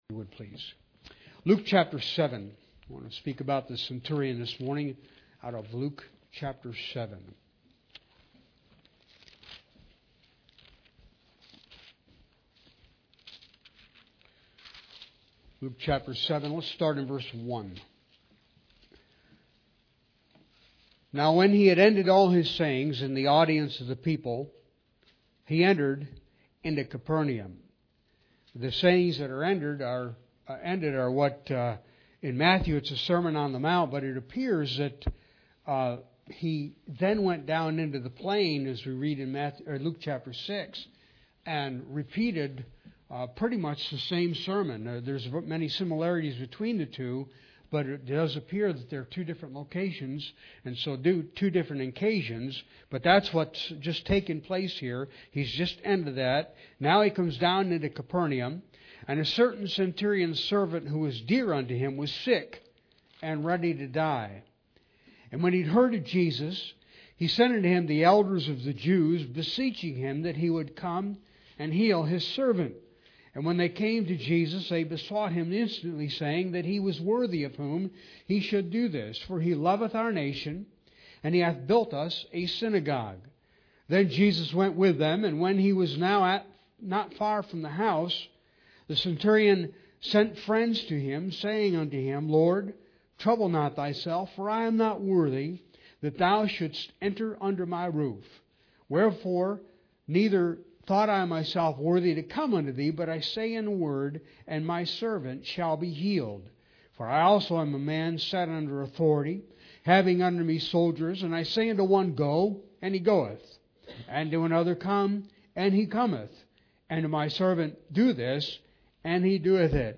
Bible Text: Luke 7:1-10 | Preacher